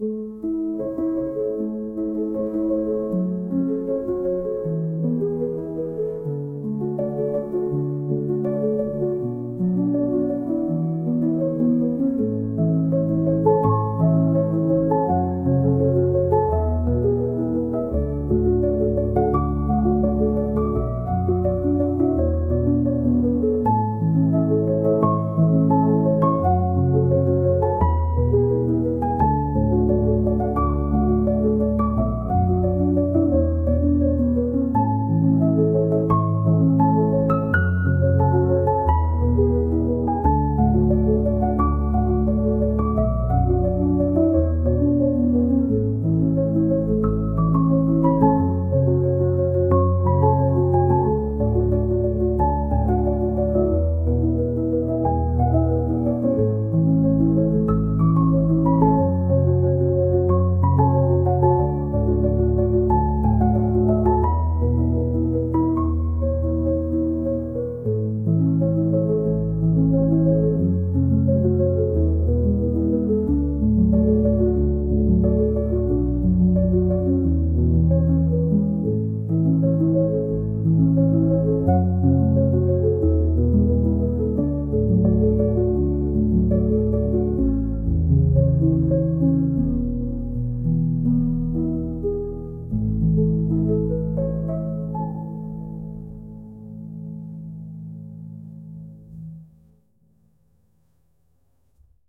「癒し、リラックス」